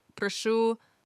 PROH-shoo you're welcome